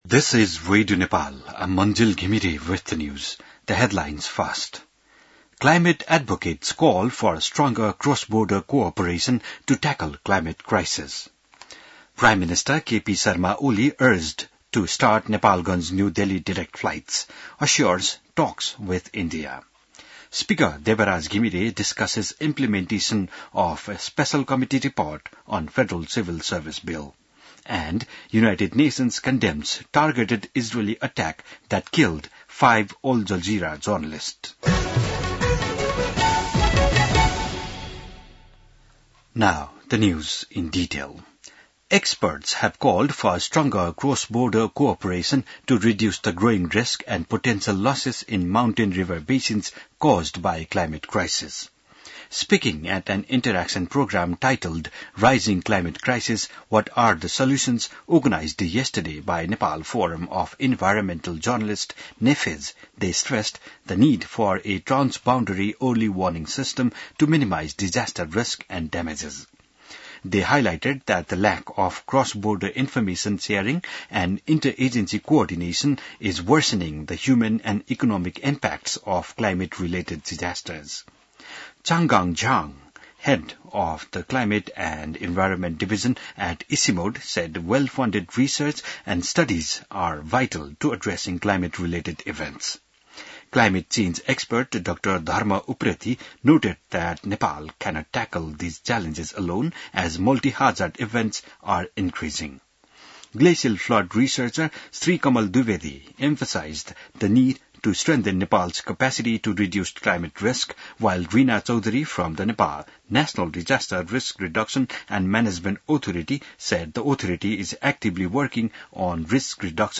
बिहान ८ बजेको अङ्ग्रेजी समाचार : २७ साउन , २०८२